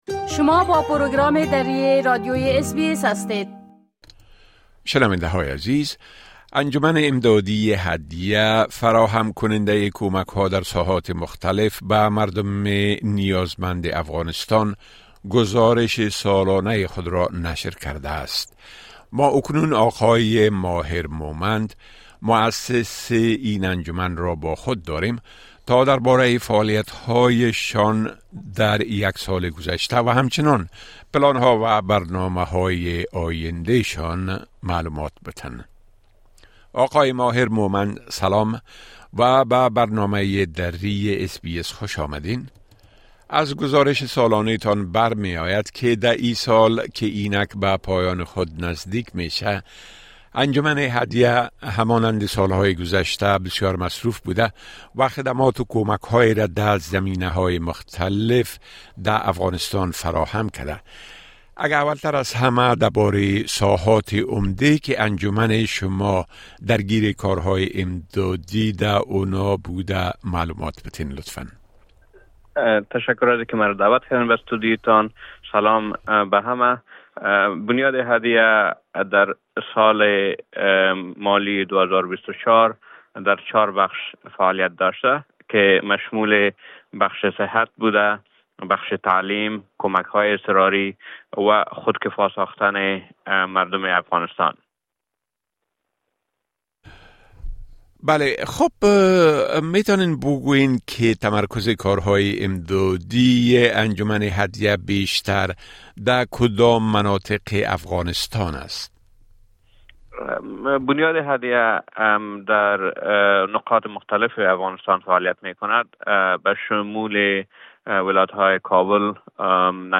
گفتگوی انجام دادیم تا در باره فعالیت‌های شان در یک سال گذشته و هم‌چنان پلان‌ها و برنامه‌های آینده شان معلومات بدهند.